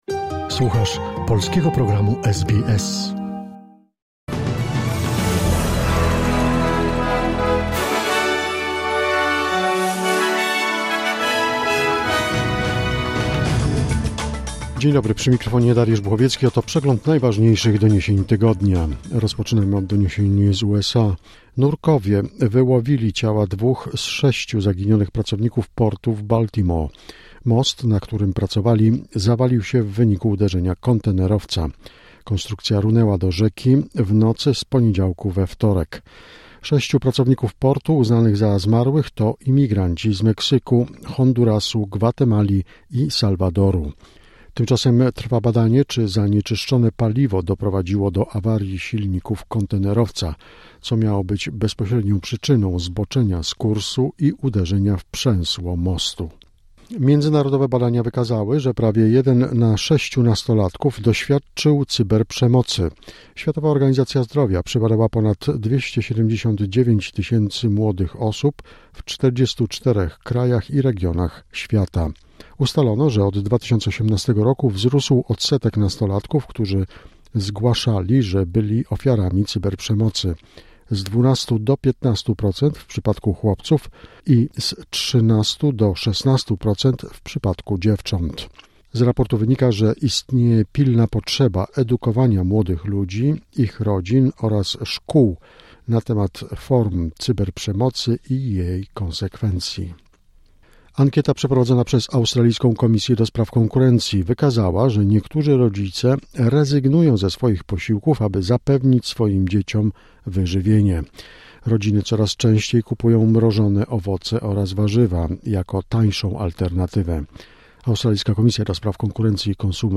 Wiadomości 28 marca 2024 - SBS News Weekly Wrap